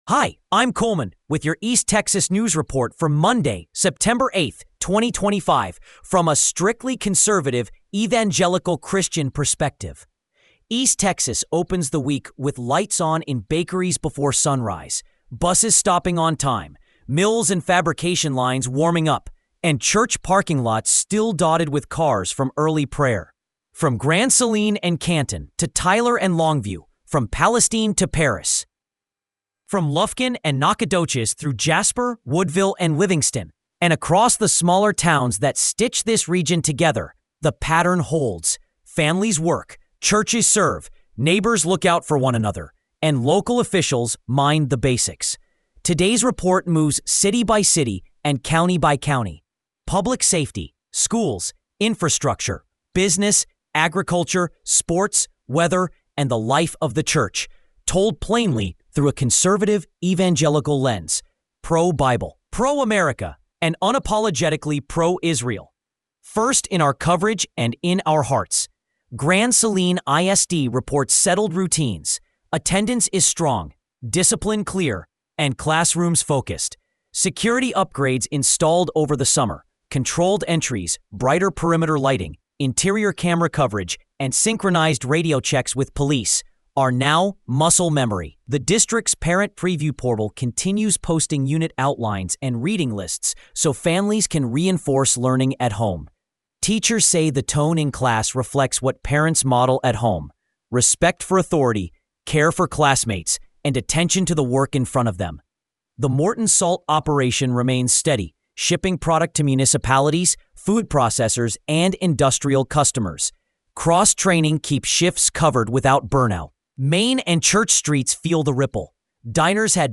East Texas News Report for Monday, September 8, 2025